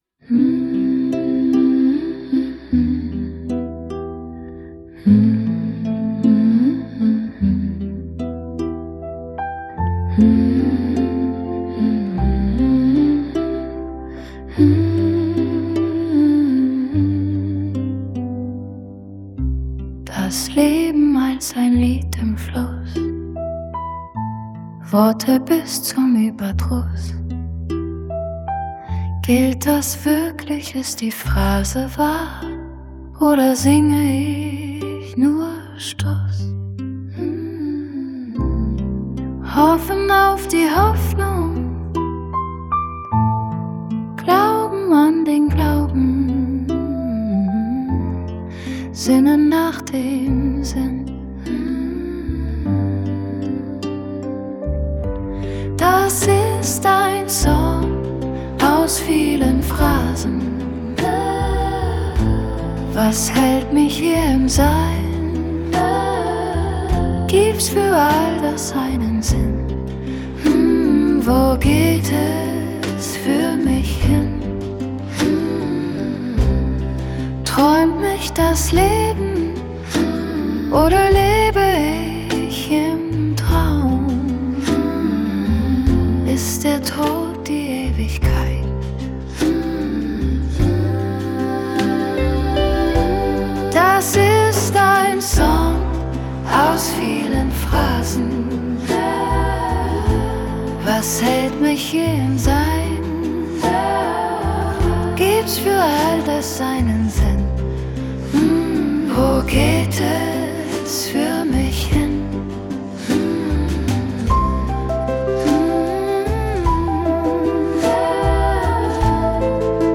Lebensphrasen – weiblicher Singer Songwriter, Harfe, Piano